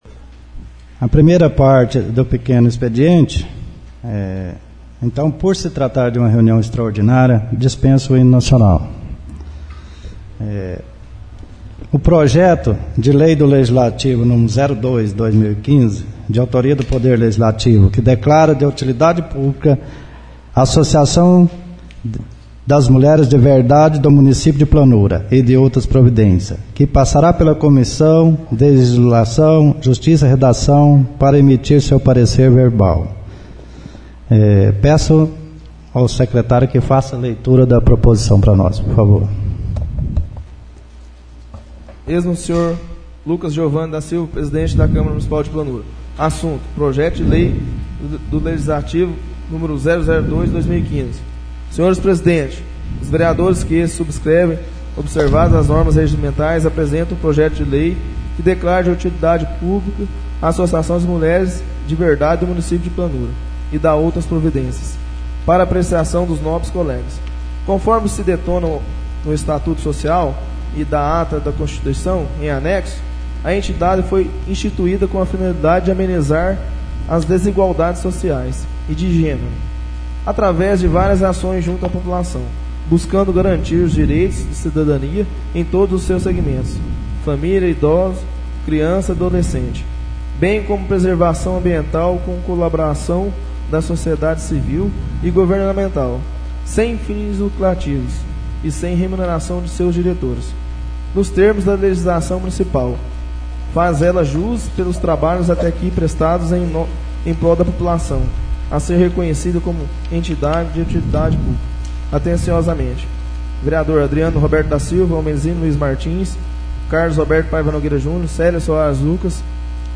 Sessão Extraordinária - 26/03/15 — CÂMARA MUNICIPAL DE PLANURA